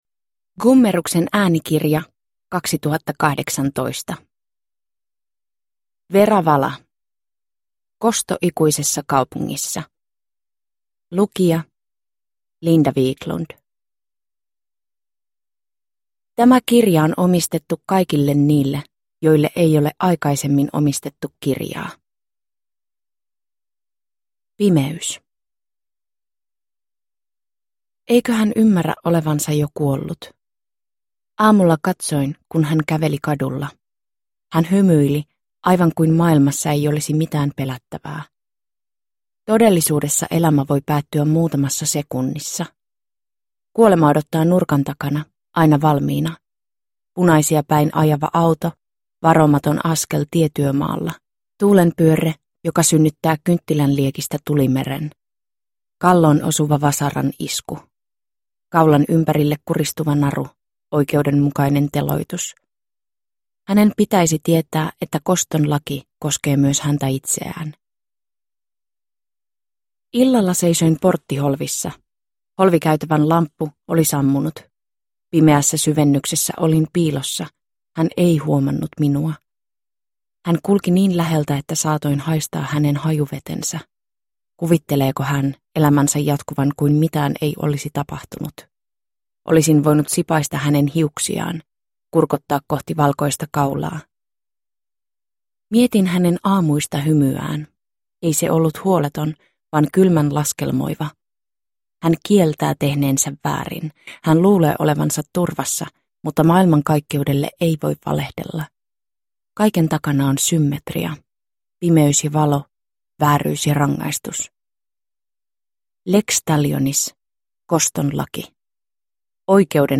Kosto ikuisessa kaupungissa (ljudbok) av Vera Vala